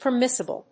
音節per・mis・si・ble 発音記号・読み方
/pɚmísəbl(米国英語), pəmísəbl(英国英語)/